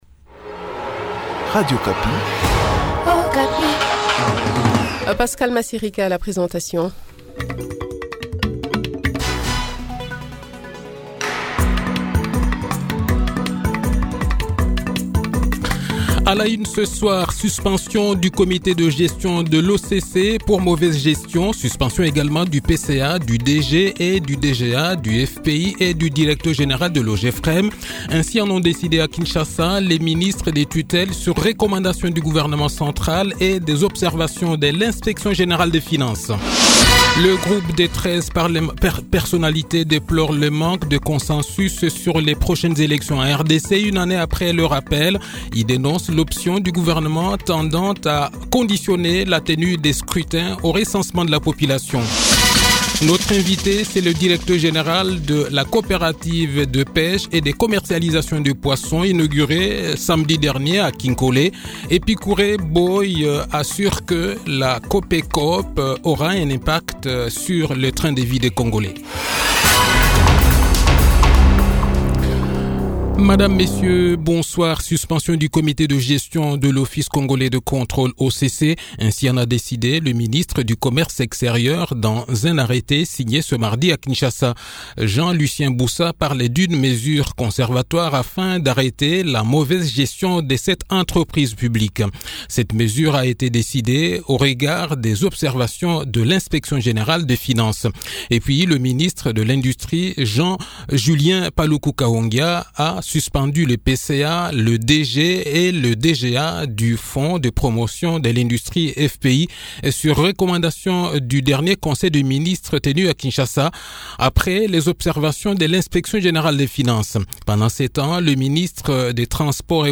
Le journal de 18 h, 13 Juillet 2021